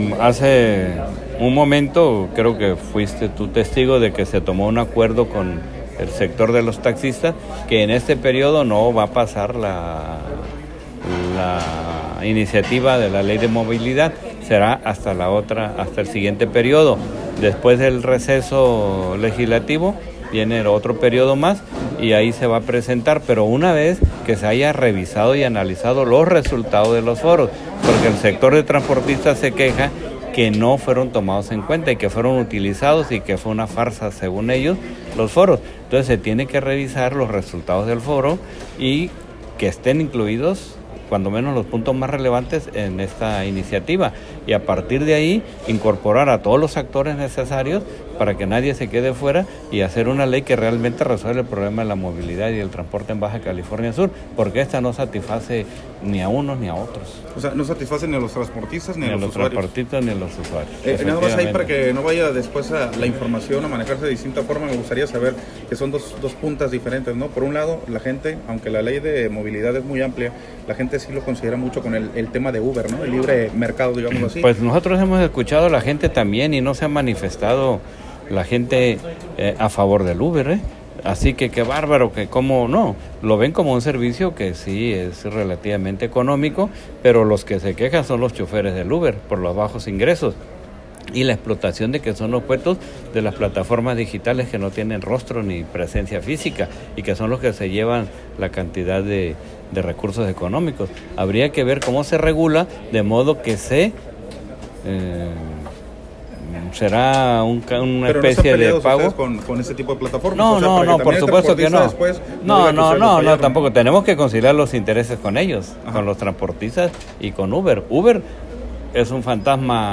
Entrevista con el diputado Esteban Ojeda Ramírez